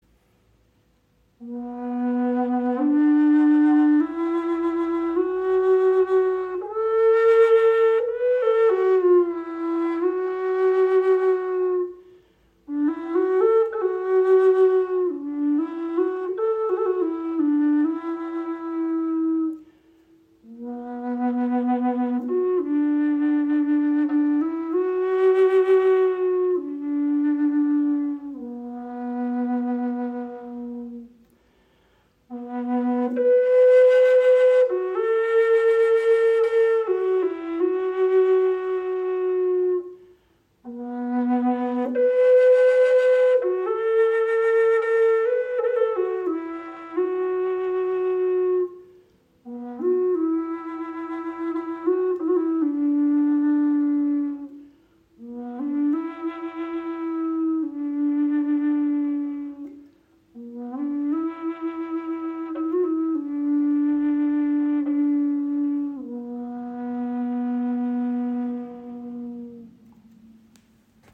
• Icon Warmer, erdender Basston mit tief berührender Präsenz
Wenn Du tiefe, ruhige und erdende Töne liebst, öffnet Dir diese Flöte einen warmen, weichen Klangraum mit einem süssen, tiefen Basston.
Entdecke die handgefertigte Gebetsflöte im Bassbereich in B-Moll.
Jede Flöte wird präzise von Hand gestimmt und entfaltet harmonische, klare Töne, die Herz und Seele berühren.